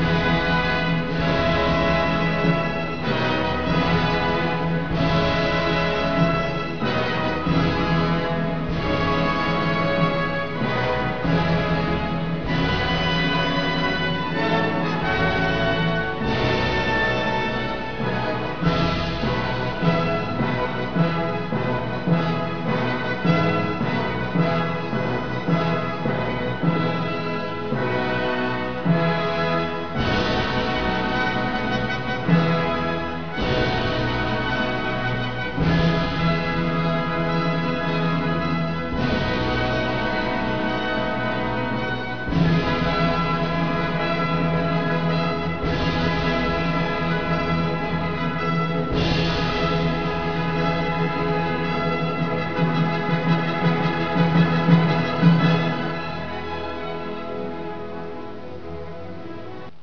Motivo del Vysehrad: la Moldava passa sotto il castello di Vyserhad, a Praga,  e lo saluta mentre echeggiano le note dell'inno nazionale.